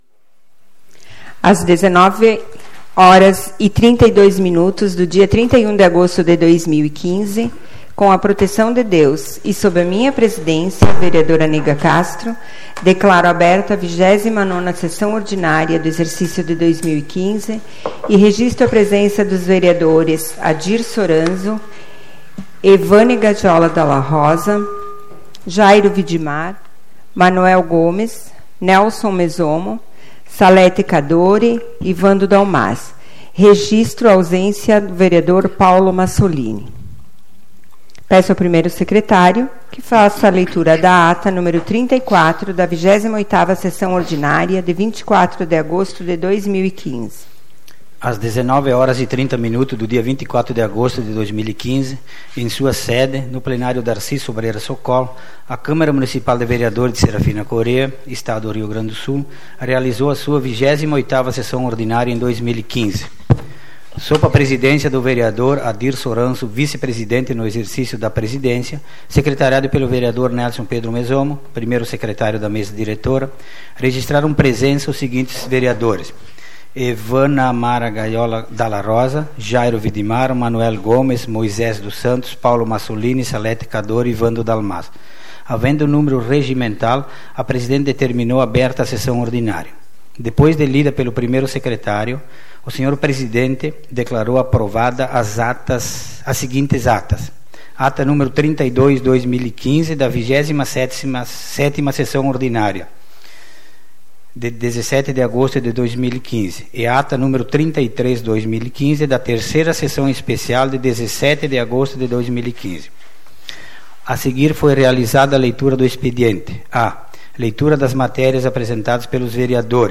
Leitura da Ata nº 34/2015, da 28ª Sessão Ordinária, de 24 de agosto de 2015.